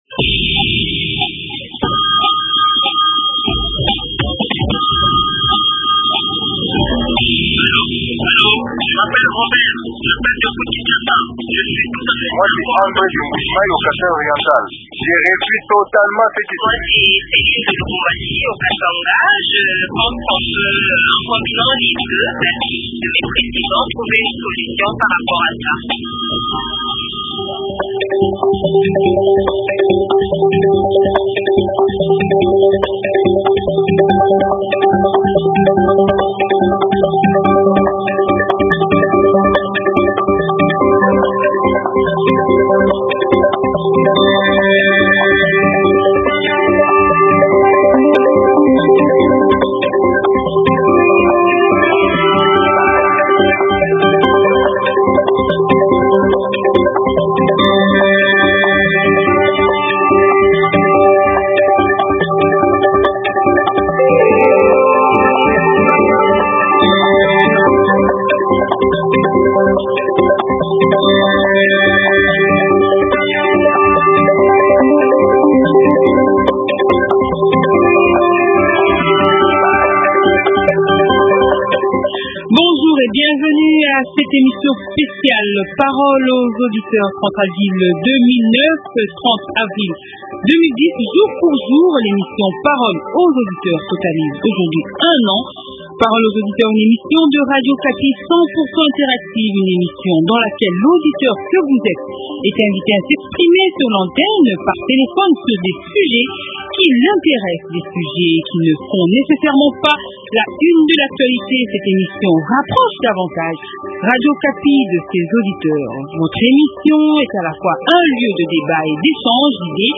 L'an 1 de "Parole aux auditeurs", l'émission 100% interactive de Radio Okapi